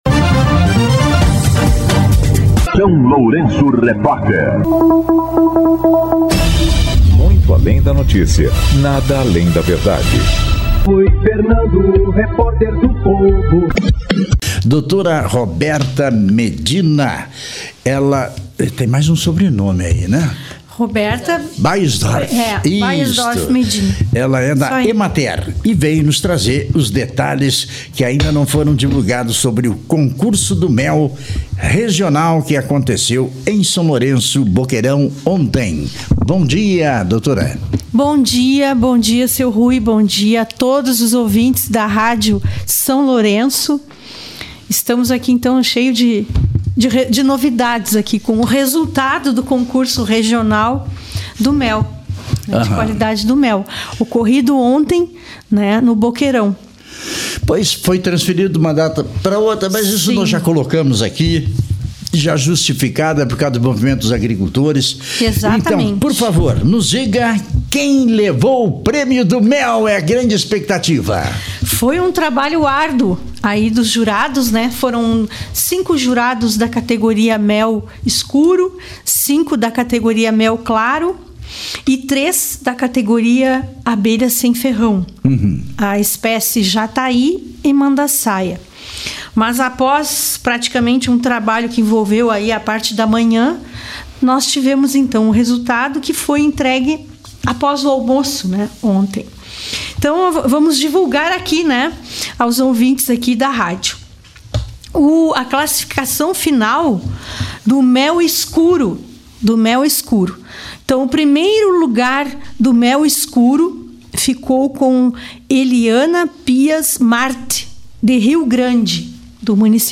O SLR RÁDIO conversou na manhã desta quarta-feira (4)